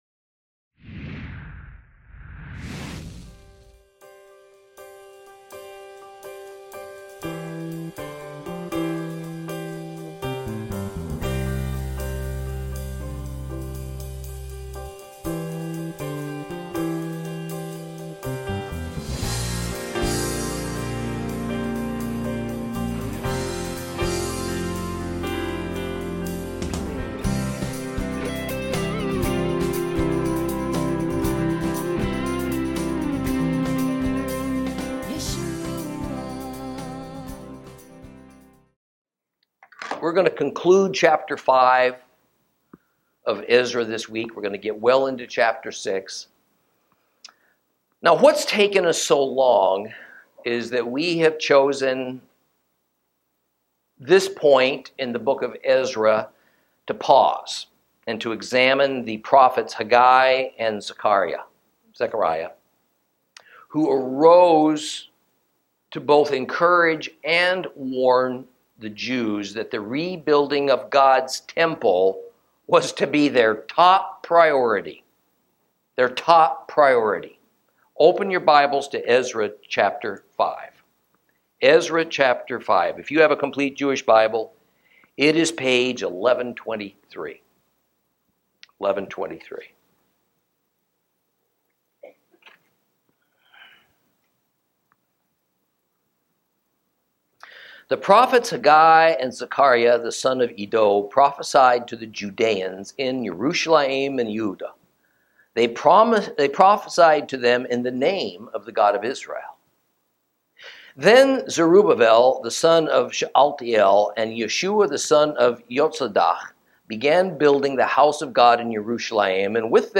Lesson 11 Ch5 Ch6 - Torah Class